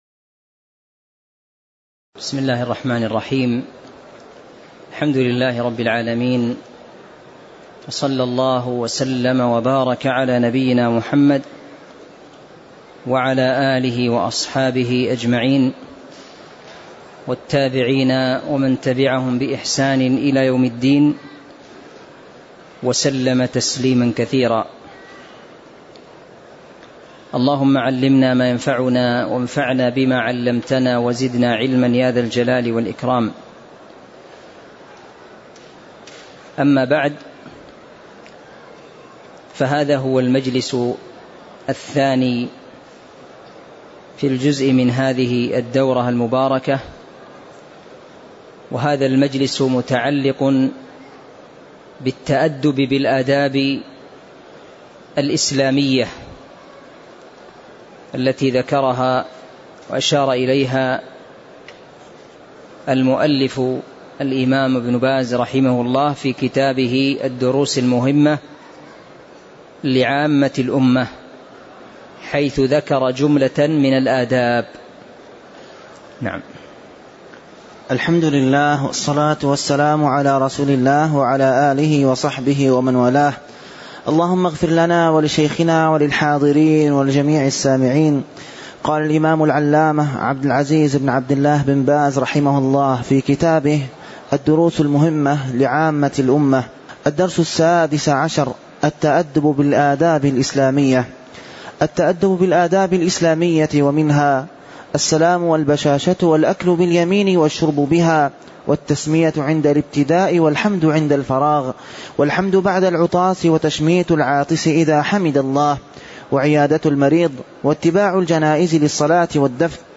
الدرس السادس عشر التأدب بالآداب الإسلامية (02) - الموقع الرسمي لرئاسة الشؤون الدينية بالمسجد النبوي والمسجد الحرام
المكان: المسجد النبوي